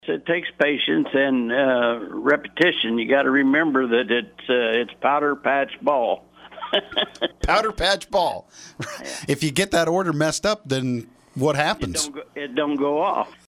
pwder-patch-ball.mp3